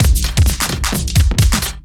OTG_DuoSwingMixC_130b.wav